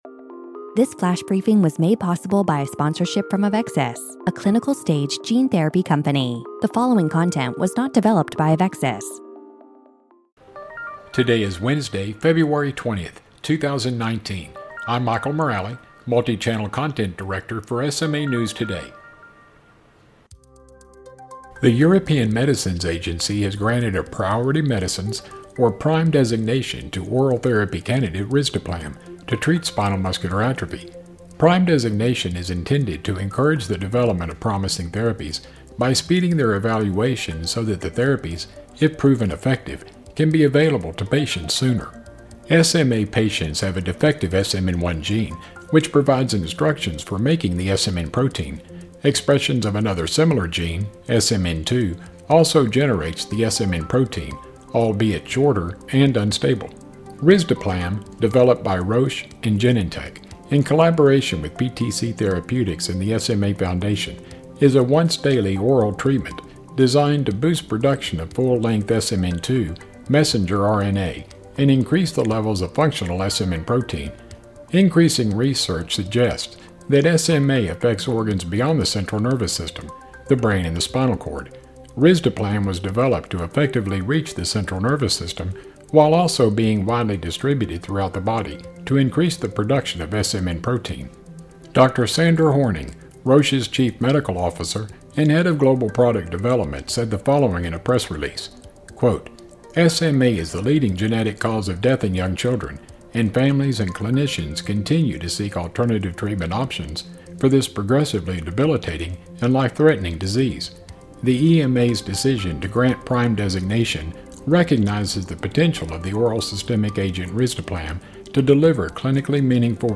reads from his latest column, in which he encourages parents to let their children with SMA have big dreams.